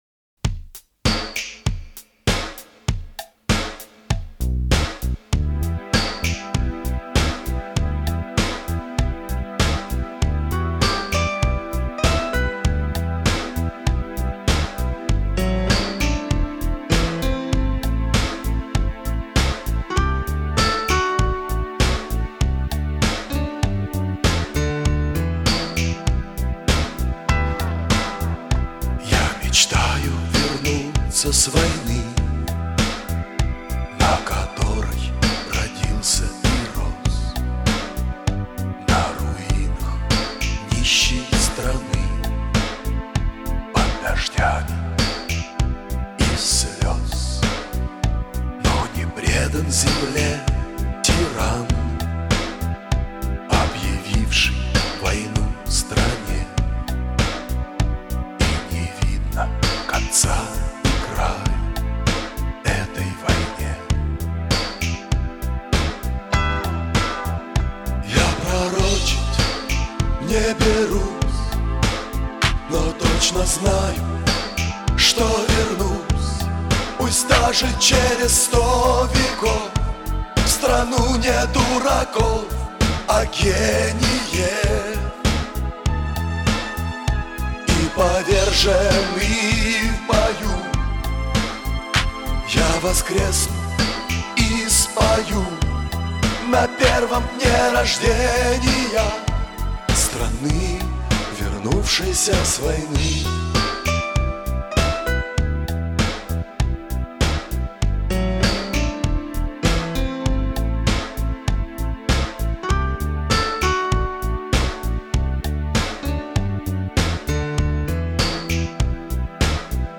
СТЕРЕО И С КОНЦОВКОЙ